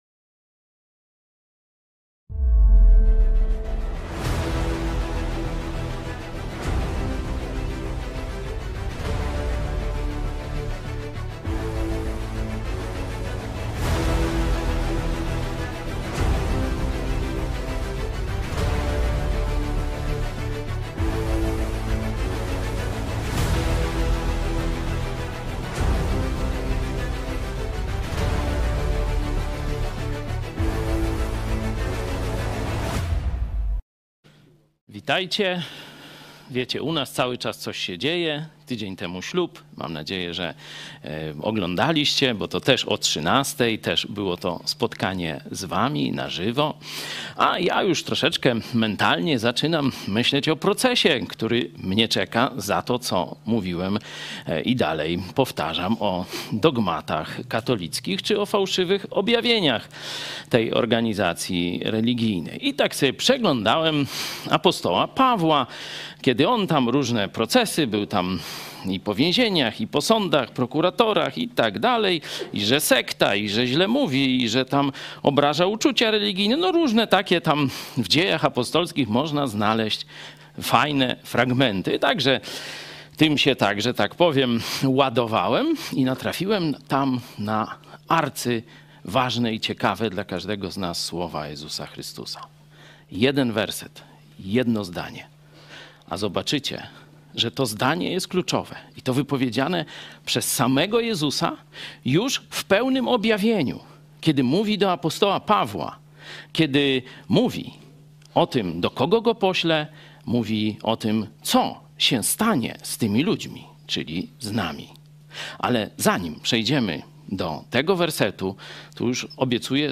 Nauczanie